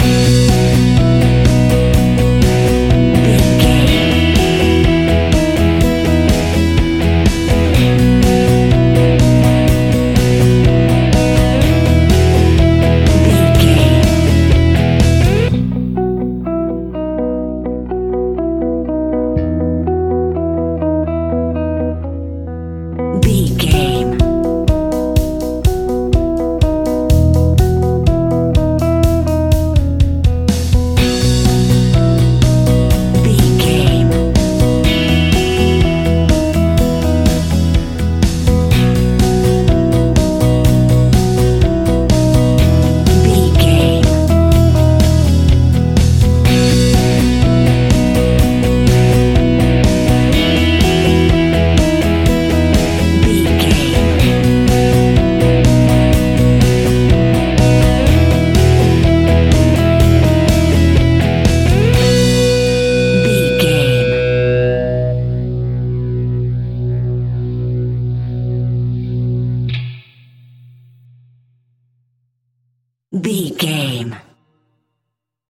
Ionian/Major
indie pop
fun
energetic
uplifting
cheesy
instrumentals
guitars
bass
drums
piano
organ